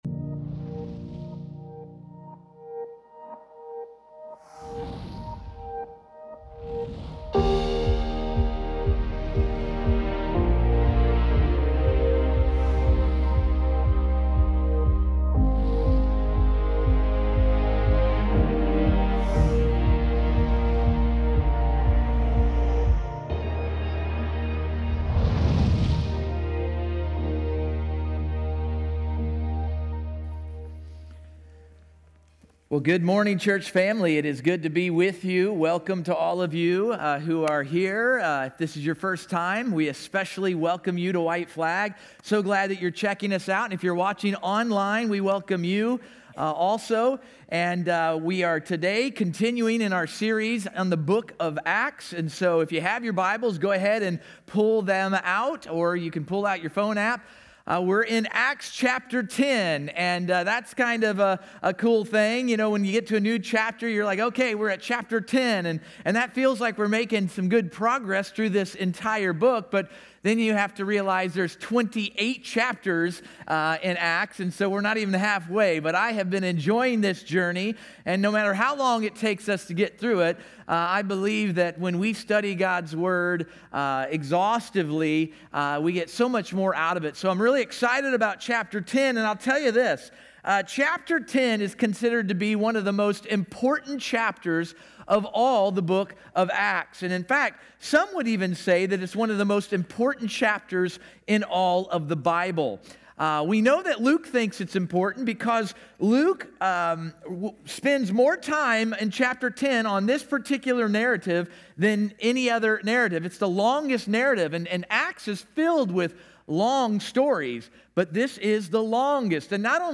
Sermons
acts-may4-sermonaudio.mp3